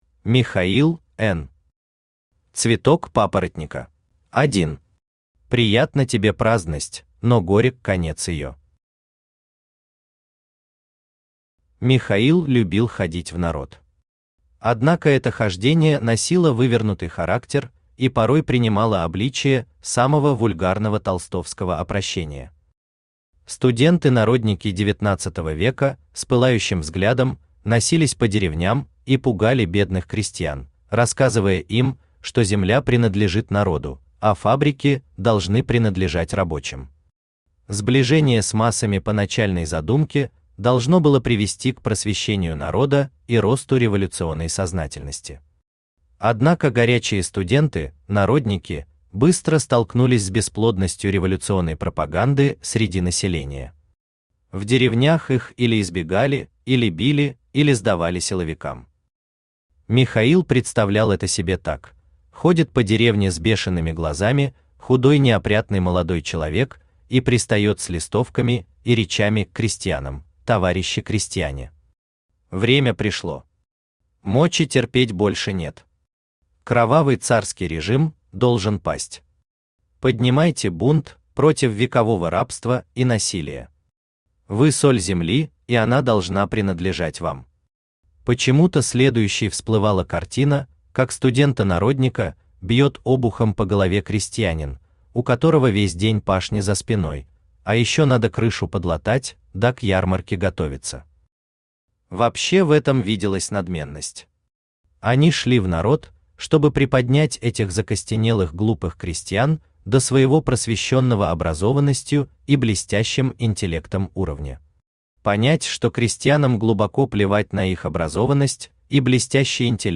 Аудиокнига Цветок папоротника | Библиотека аудиокниг
Читает аудиокнигу Авточтец ЛитРес.